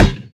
ballHit.wav